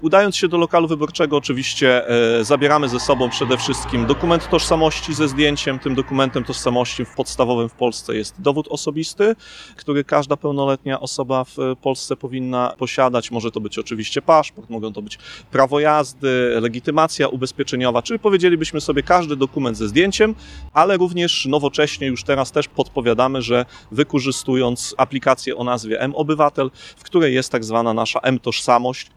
Marcin Radziłowicz, Sekretarz Miasta Ełku wylicza, że może to być dowód osobisty, prawo jazdy, ale także… aplikacja mObywatel w telefonie.